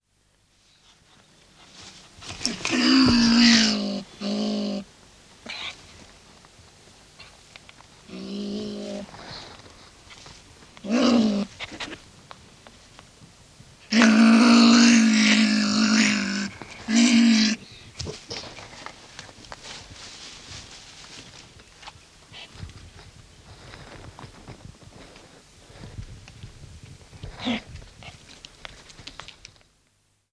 Baummarder - Der Edelmarder
Baummarder-Geraeusche-Wildtiere-in-Europa.wav